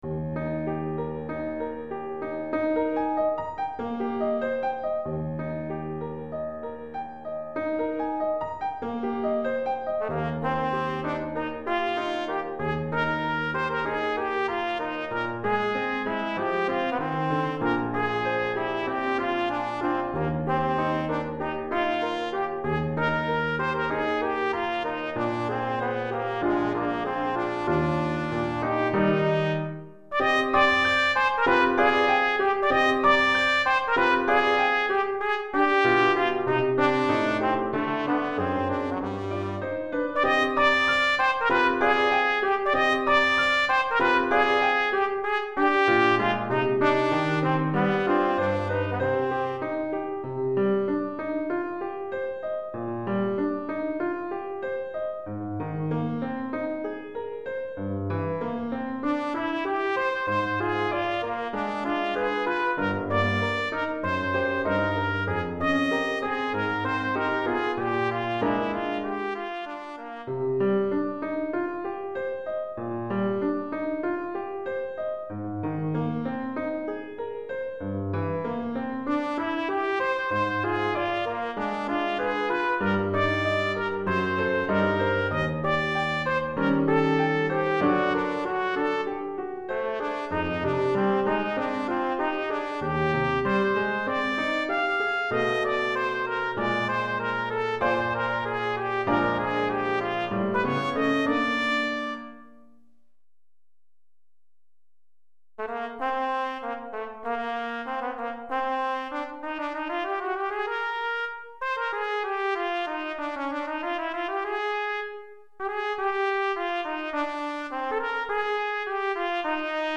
Trompette et Piano